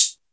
Mellow Pedal Hi-Hat A# Key 154.wav
Royality free pedal hi-hat one shot tuned to the A# note. Loudest frequency: 6870Hz
mellow-pedal-hi-hat-a-sharp-key-154-7LF.mp3